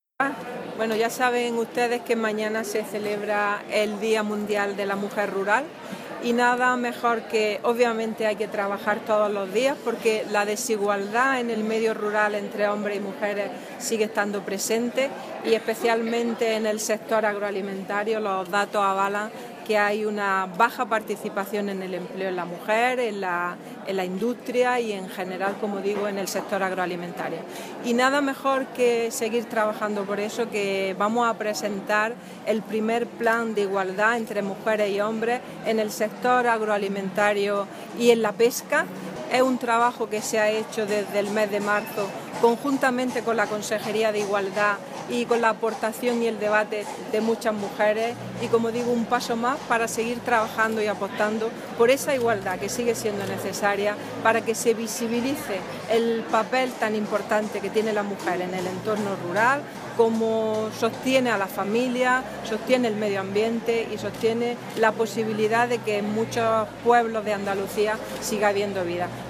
Carmen Ortiz en el acto conmemorativo del Día Internacional de la Mujer Rural
Declaraciones de Carmen Ortiz sobre la igualdad de oportunidades de mujeres y hombres en el ámbito rural